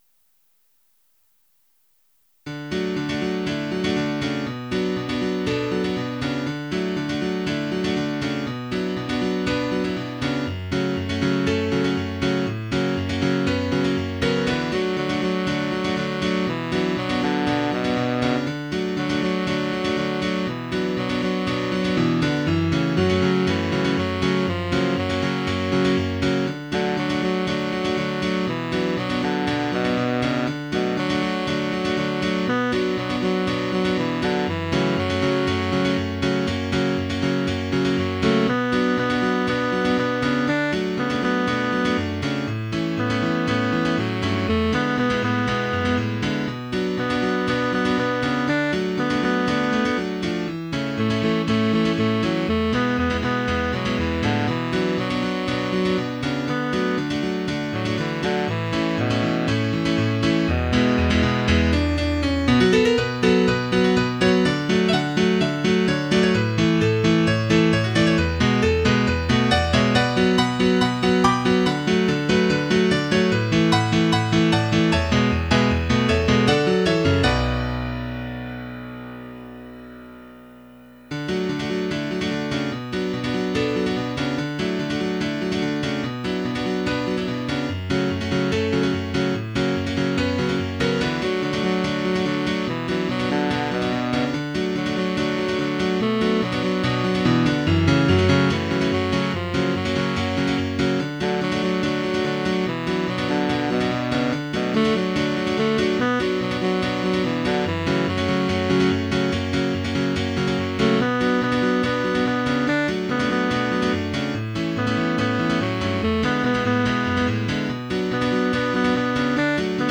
Night Owl (solo pop
Don't click away too quickly - give the coda a shot first (m. 42). mp3 download wav download Files: wav mp3 Tags: Duet, Piano, Choral Plays: 1413 Likes: 0